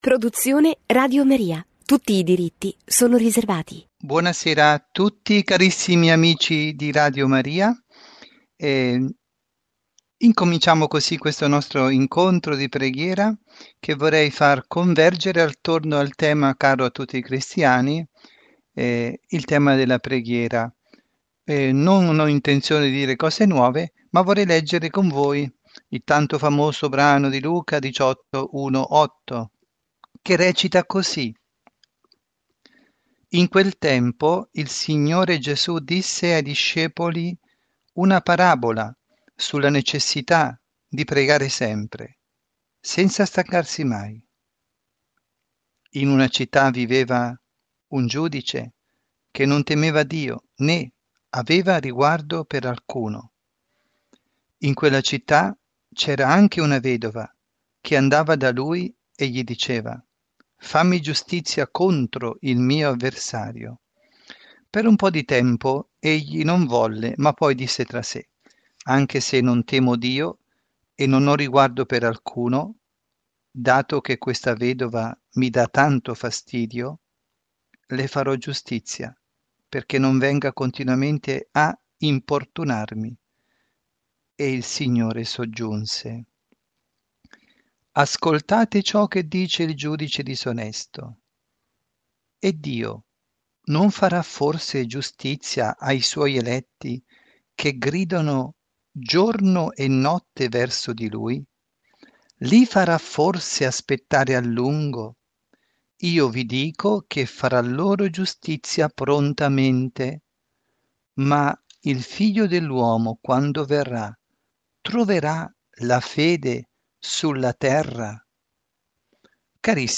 trasmessa in diretta su RadioMaria
Catechesi